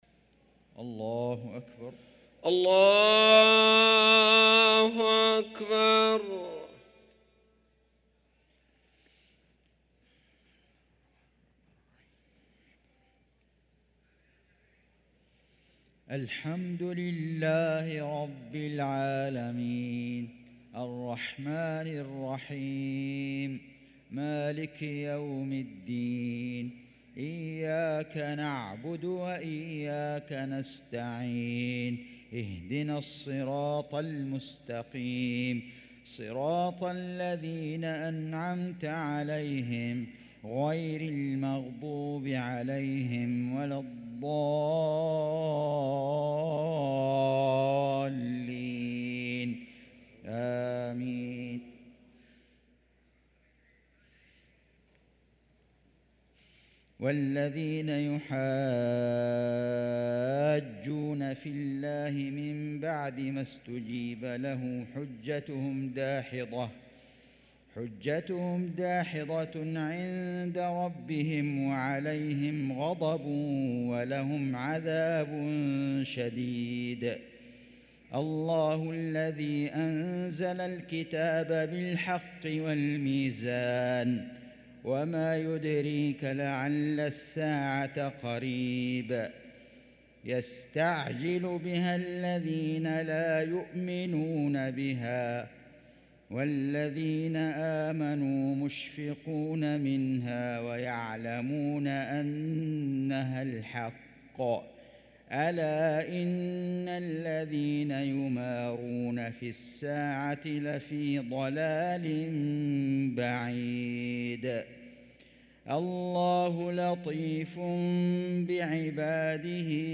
صلاة المغرب للقارئ فيصل غزاوي 7 رجب 1445 هـ